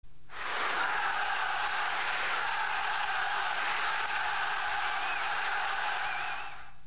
Звуки спорта (200)
В этой стране футбольные стадионы поют, дразнят соперников, заводят своих так, как на стадионе «Холуд Энд», чей шум на аудиодорожке.